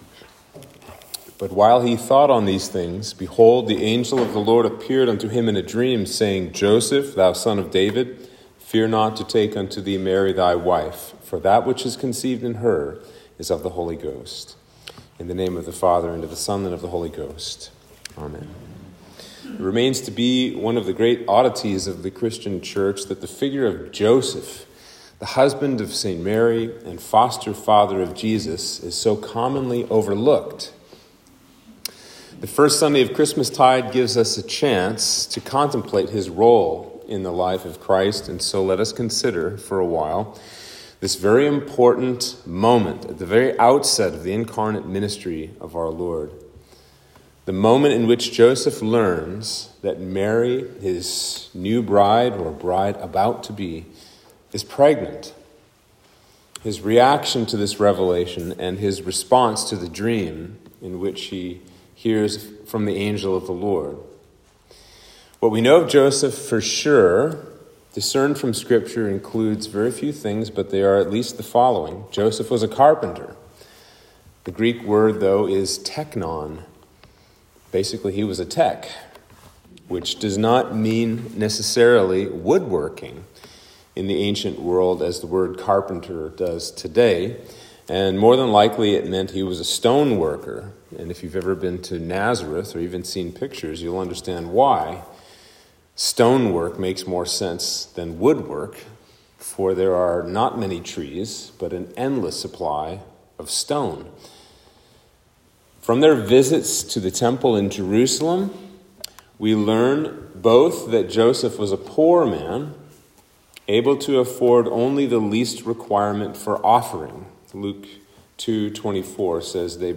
Sermon for Christmas 1